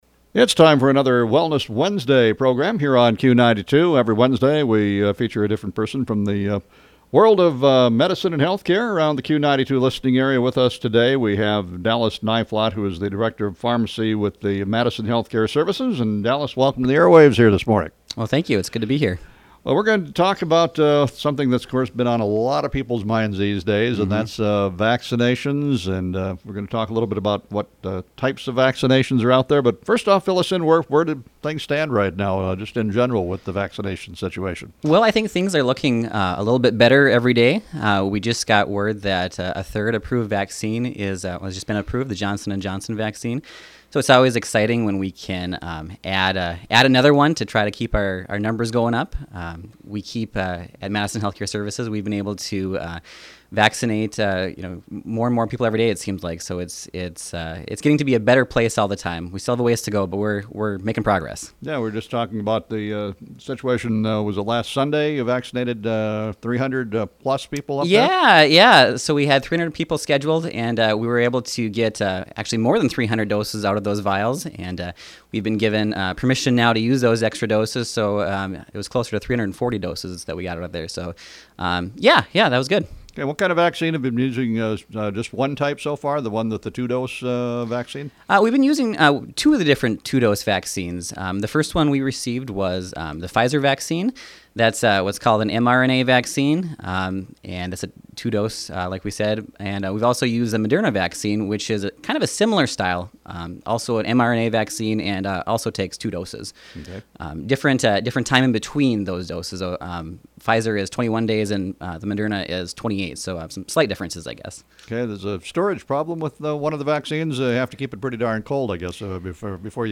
Under: Interviews, Wellness Wednesday